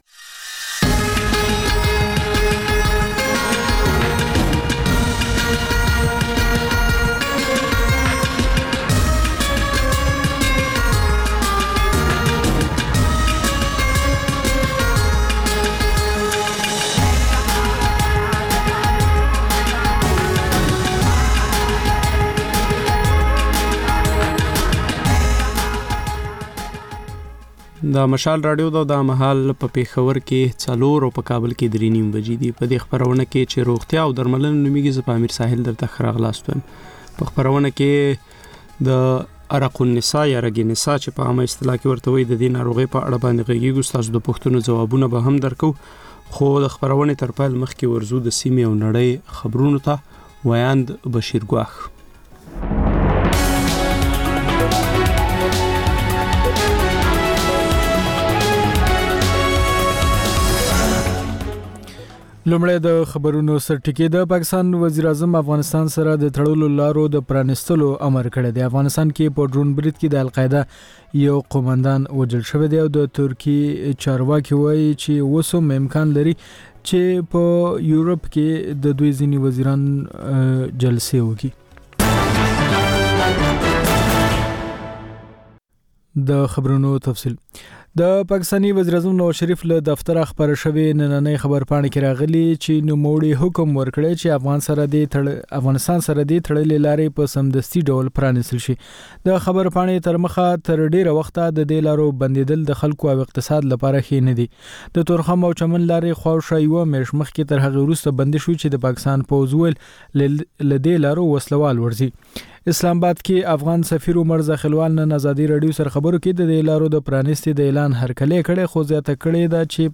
د مشال راډیو مازیګرنۍ خپرونه. د خپرونې پیل له خبرونو کېږي. د دوشنبې یا د ګل پر ورځ د روغتیا په اړه ژوندۍ خپرونه روغتیا او درملنه خپرېږي چې په کې یو ډاکتر د یوې ځانګړې ناروغۍ په اړه د خلکو پوښتنو ته د ټیلي فون له لارې ځواب وايي.